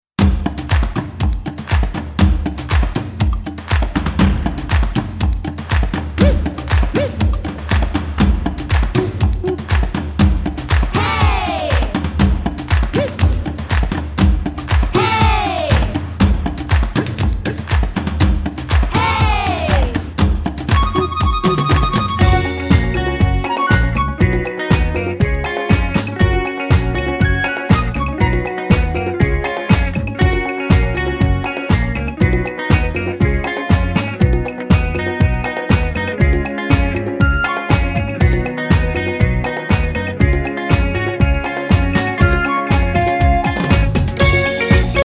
Listen to a sample of the instrumental version.